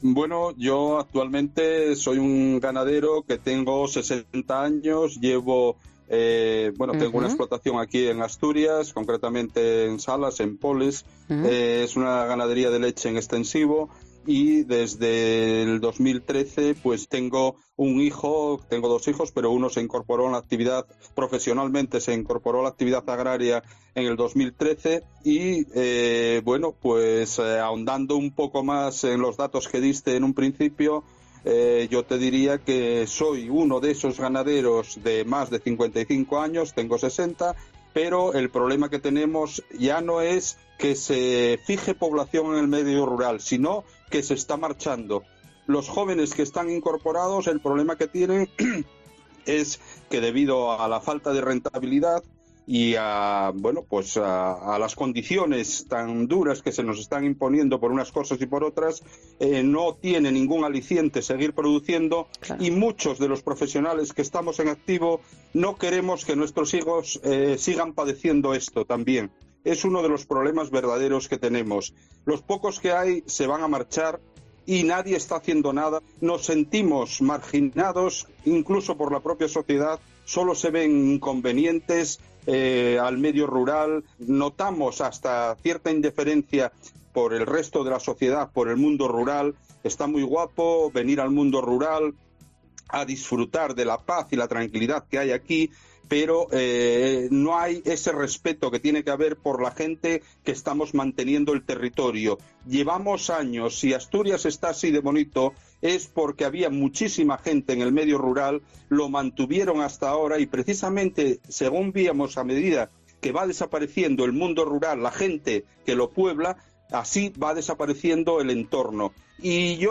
Un ganadero de Asturias avisa de un desastre monumental que ocurrirá para los turistas